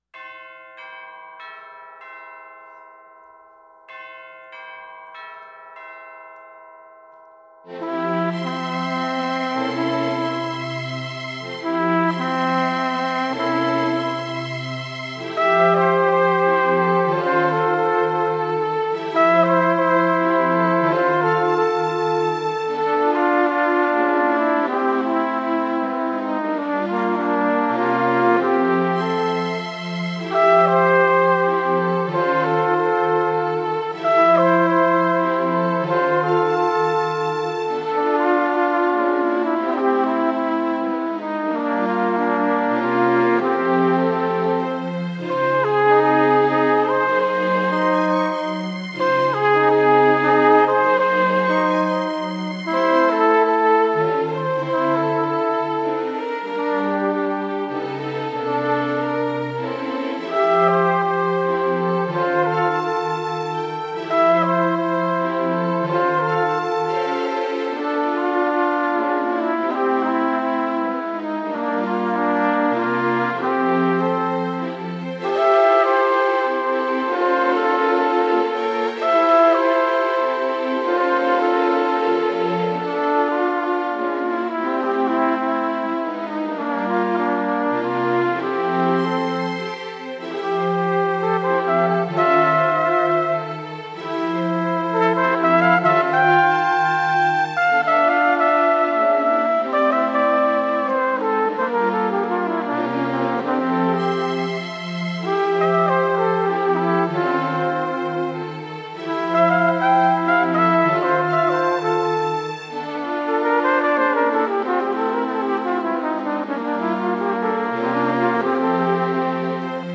Trumpet Duet /w Solo Tracks